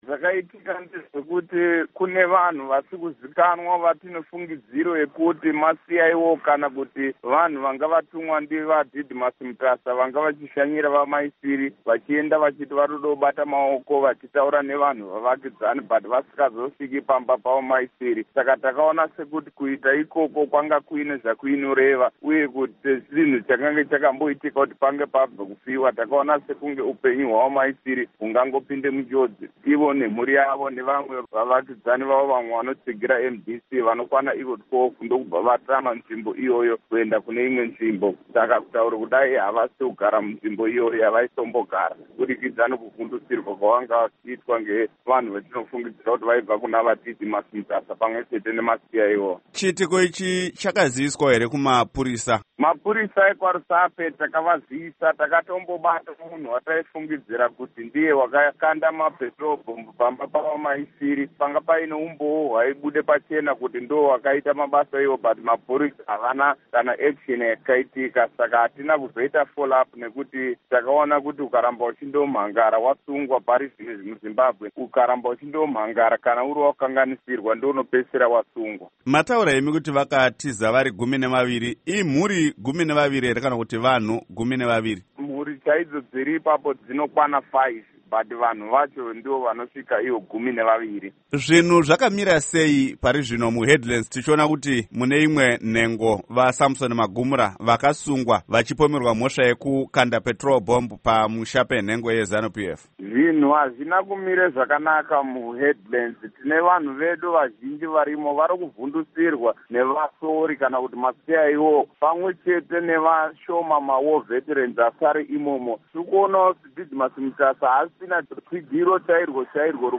Hurukuro naVaPishai Muchauraya